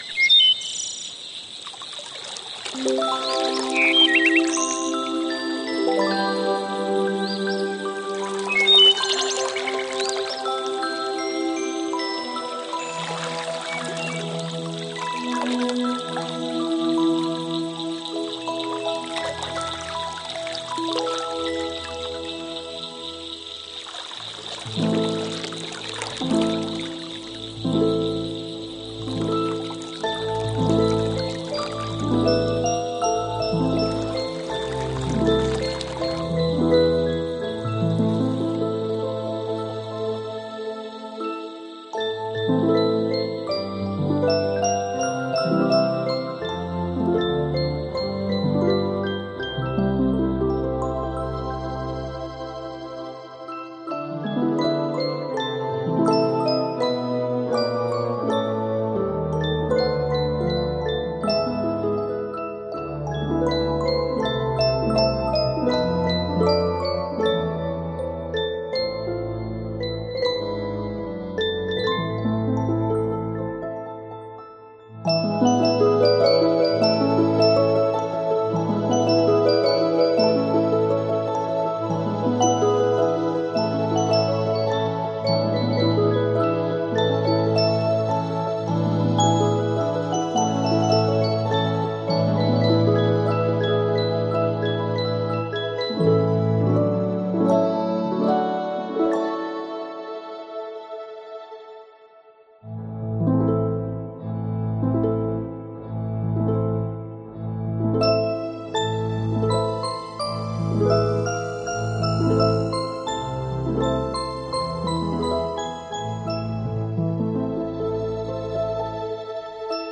А это ксилофон — музыкальный инструмент, который делает звучание уже знакомой нам мелодии волшебной, сказочной …
Ksilofon-YE.Grig-Lesnoe-Ozero-Pesnya-Solveyg.mp3